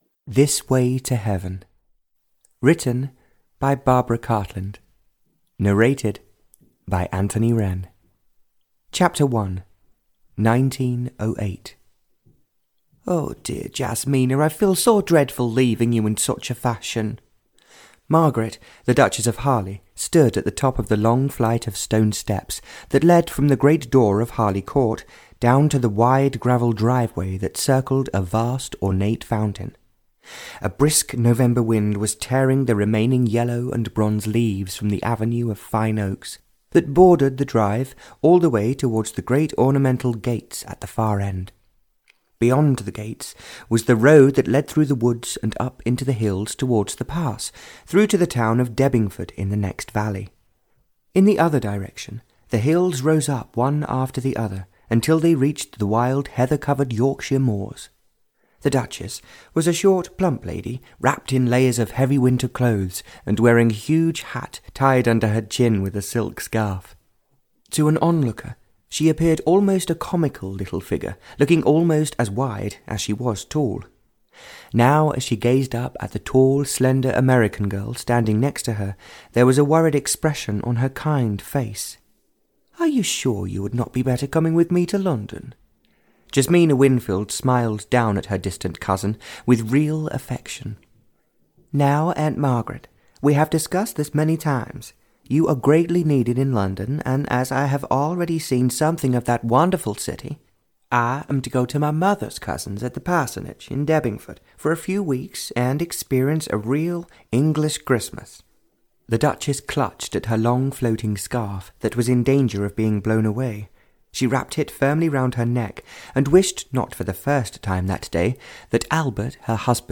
This Way to Heaven (EN) audiokniha
Ukázka z knihy